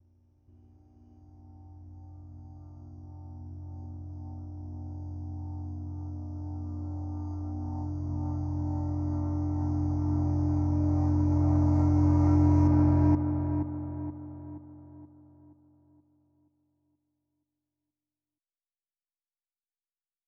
Industrial Noise FX.wav